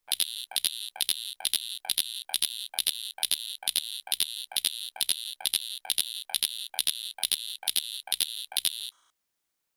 دانلود آهنگ ساعت 10 از افکت صوتی اشیاء
جلوه های صوتی
دانلود صدای ساعت 10 از ساعد نیوز با لینک مستقیم و کیفیت بالا